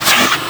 c_viper_hit1.wav